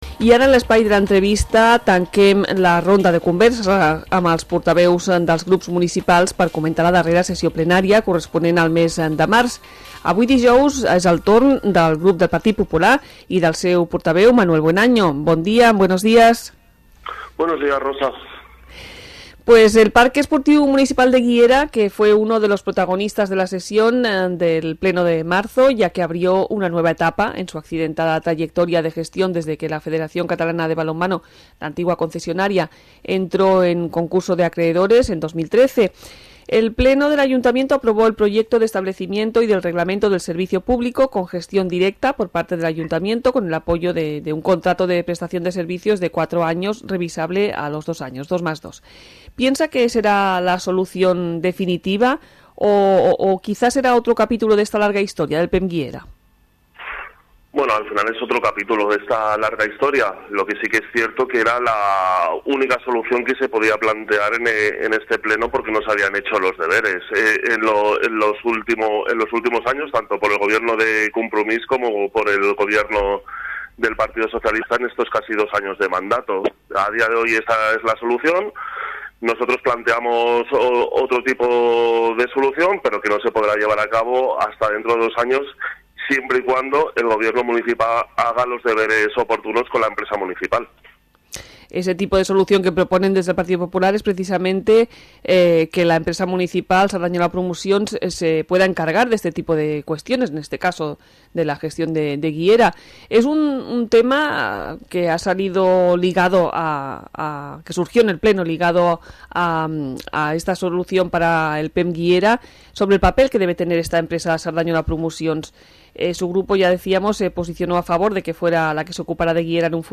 Entrevista Manuel Buenaño
Entrevista Manuel Buenaño (PP) - Ple març